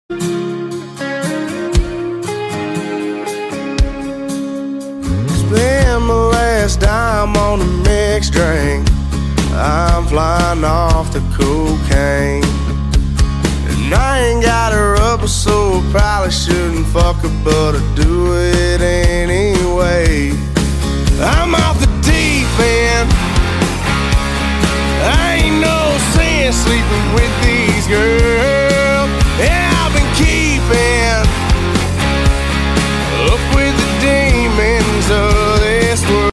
Damn 10k lbs cub cadet makin the truck squat.